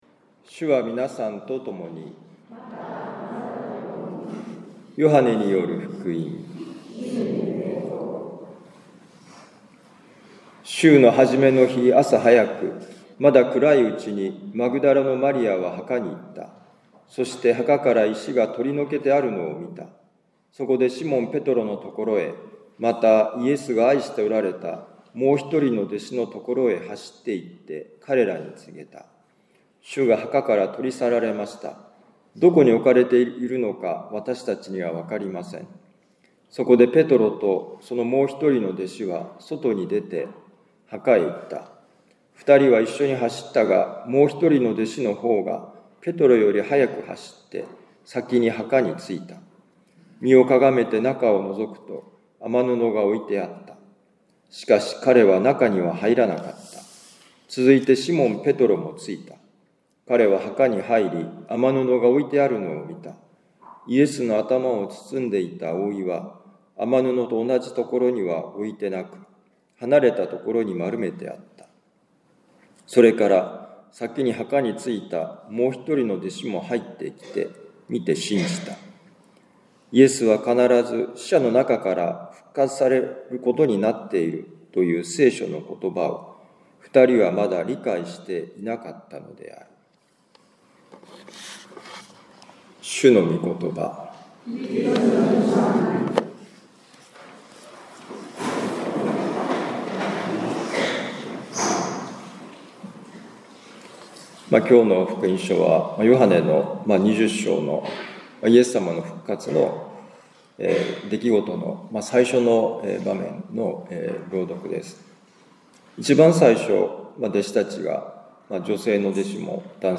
ルカ福音書24章1-12節「復活の恵みは希望」2025年4月20日復活の主日のミサ カトリック長府教会